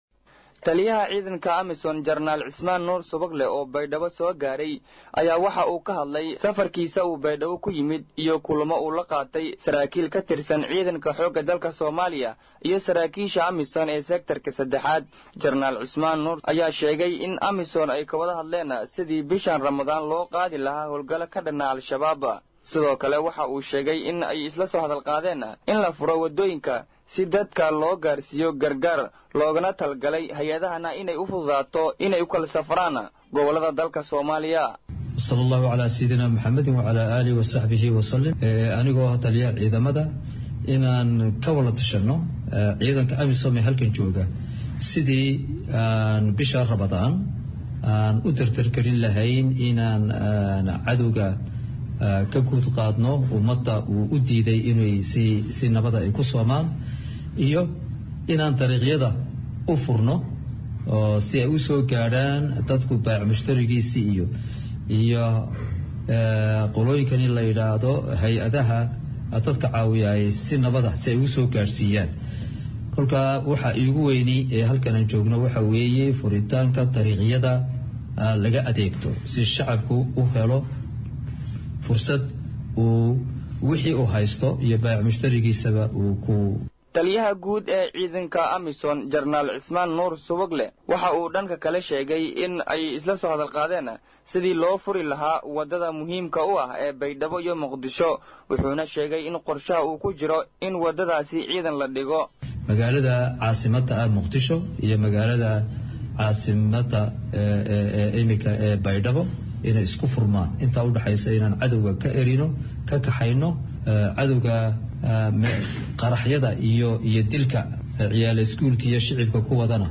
Taliyaha guud ee howlgalka midowga Africa AMISOM Jen. Cusmaan Nuur Subagle oo ayaa magaaalada Baydhabo ka sheegay in ay isku diyaarinayaan howlgalka ka dhan ah Al-shabaab.